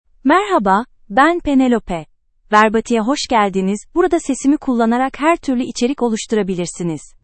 PenelopeFemale Turkish AI voice
Penelope is a female AI voice for Turkish (Turkey).
Voice sample
Female
Penelope delivers clear pronunciation with authentic Turkey Turkish intonation, making your content sound professionally produced.